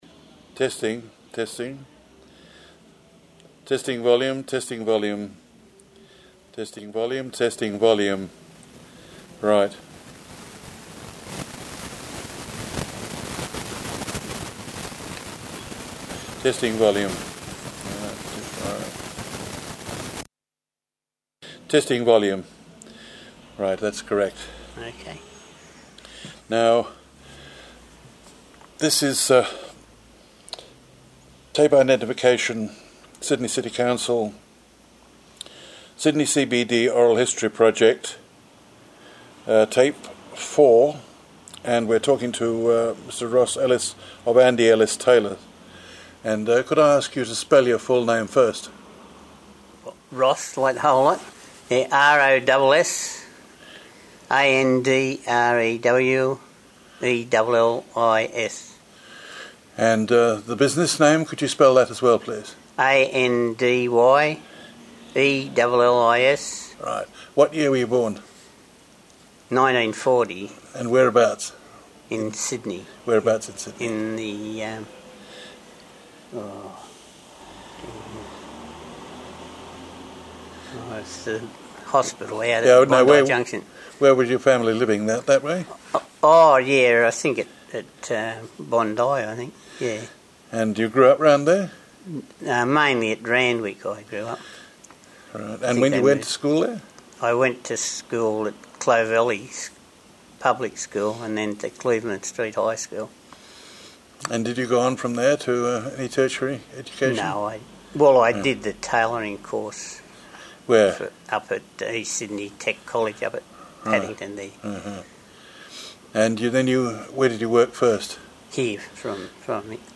This interview is part of the City of Sydney's oral history theme: Open All Hours